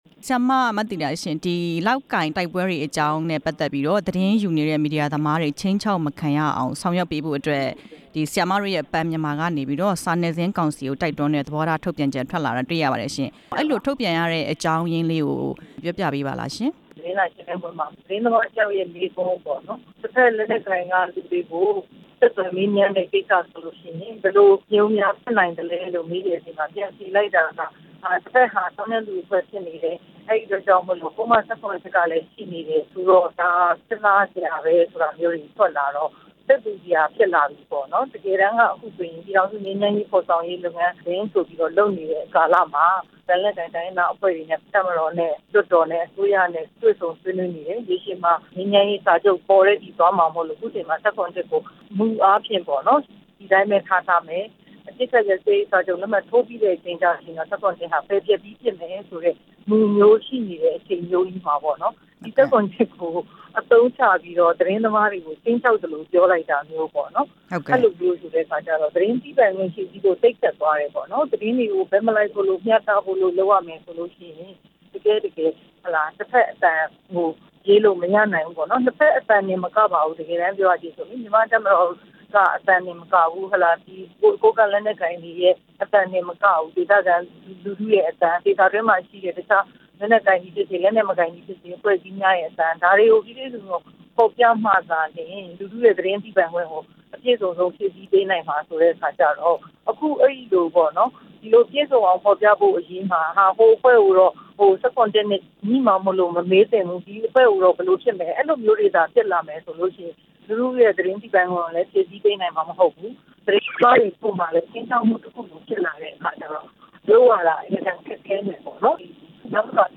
မသီတာ(စမ်းချောင်း) ကို မေးမြန်းချက်